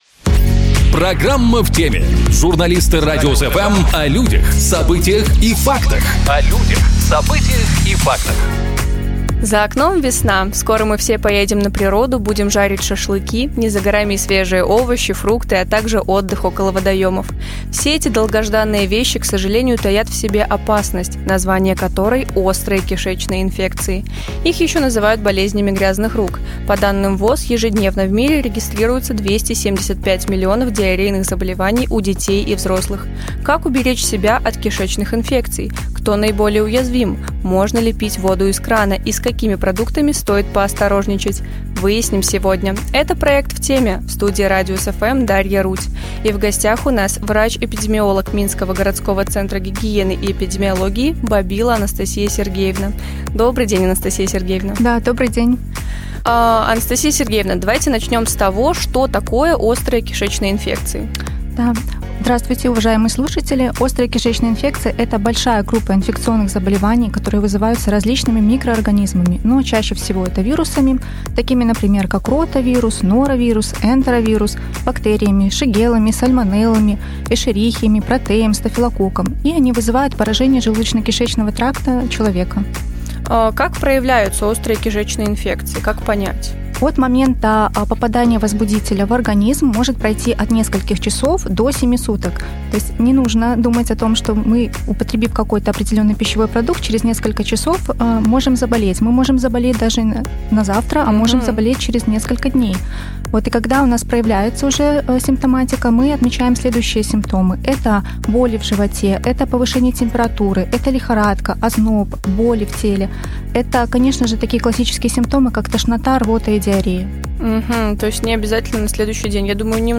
У нас гостях врач-эпидемиолог